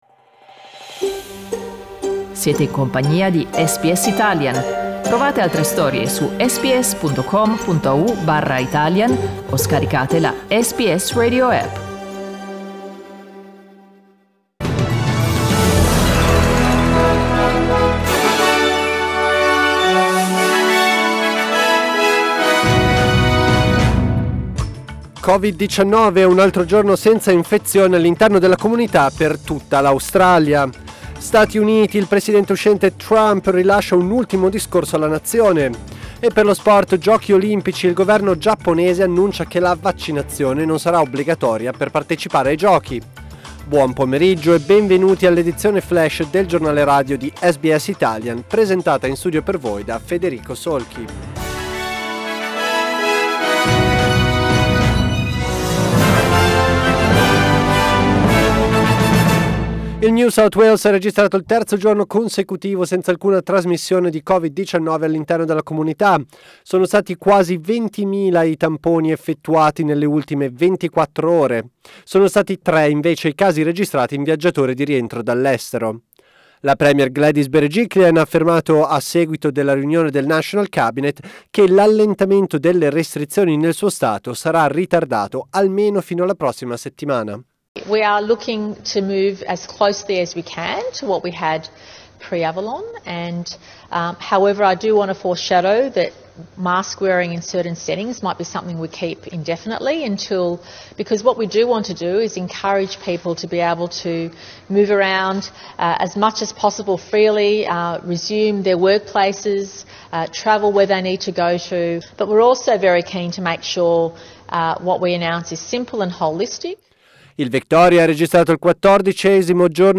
Our news update in Italian.